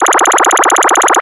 Sons et bruitages de jeux vidéos